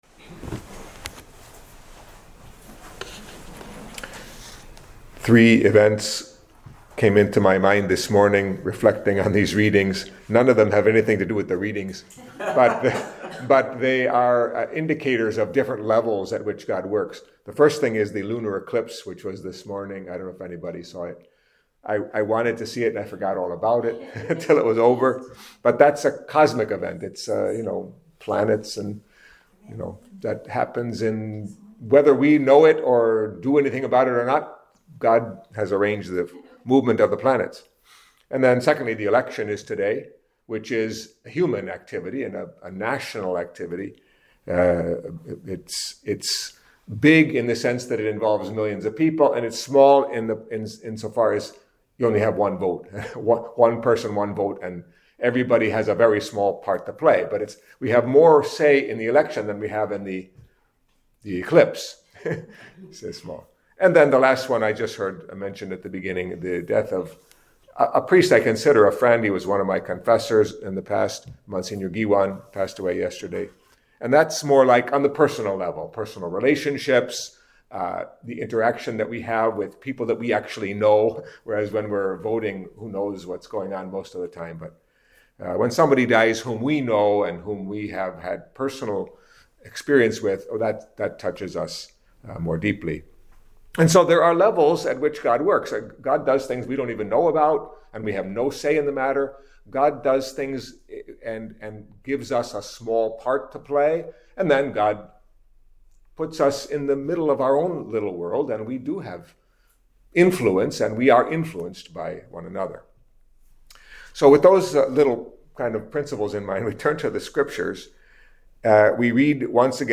Catholic Mass homily for Tuesday of the Thirty-Second Week of Ordinary Time